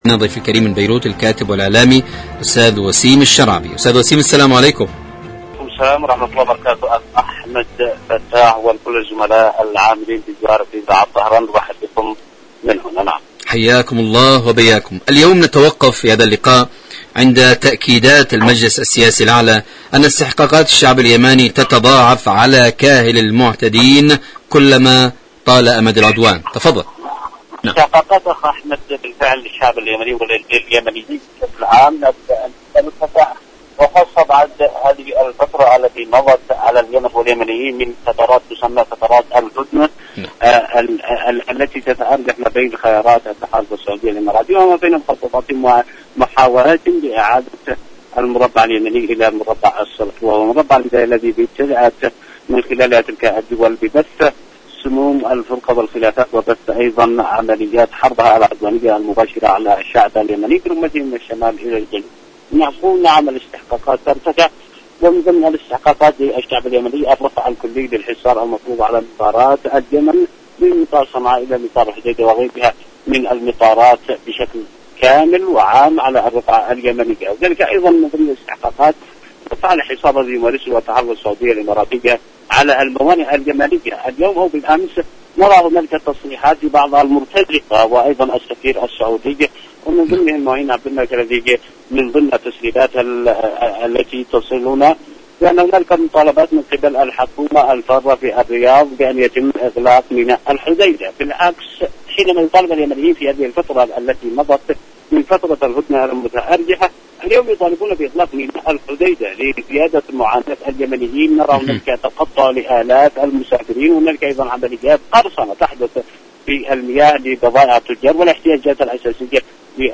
إذاعة طهران-اليمن التصدي والتحدي: مقابلة إذاعية